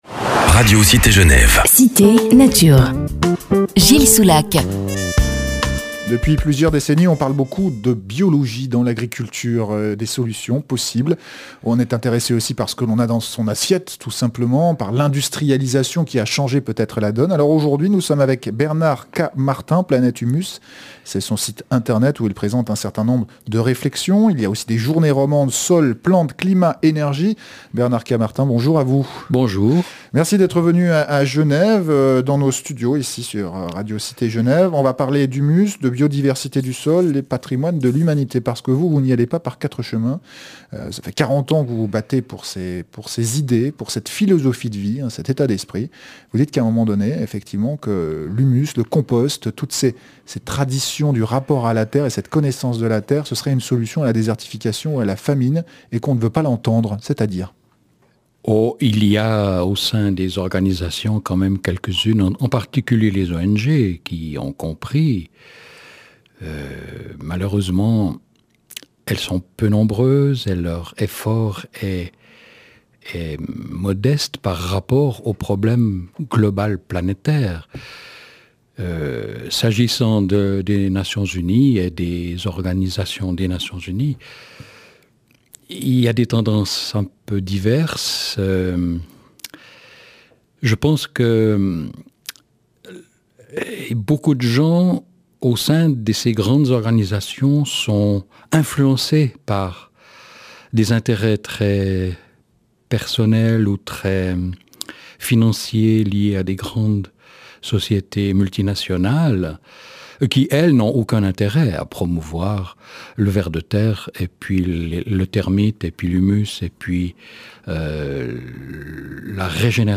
Entretien, résumé en quelques minutes d’une quarantaine d’années d’activités et d’expériences dans le domaine de la régénération des sols, la lutte contre l’érosion et la désertification, la prévention des famines et des sécheresses.